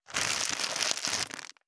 623コンビニ袋,ゴミ袋,スーパーの袋,袋,買い出しの音,ゴミ出しの音,袋を運ぶ音,
効果音